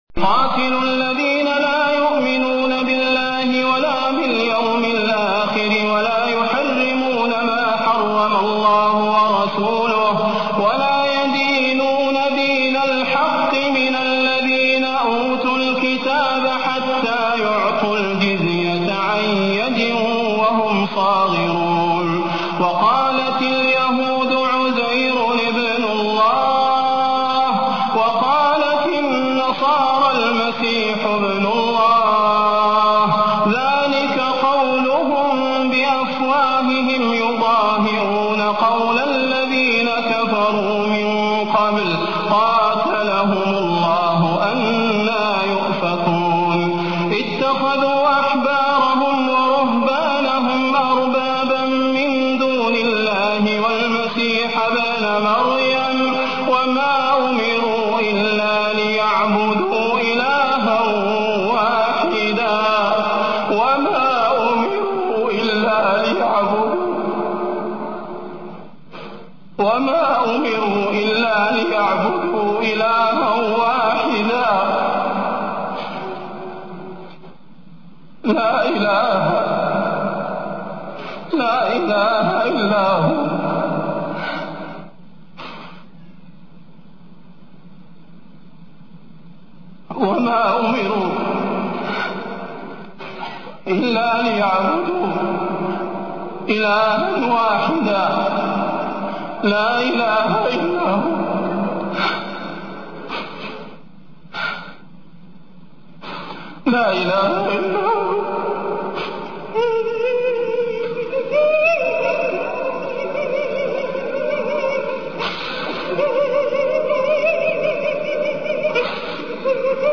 الشيخ صلاح البدير خشوع وأي خشوع في آية لا تبكي الكثير اية في توحيد الله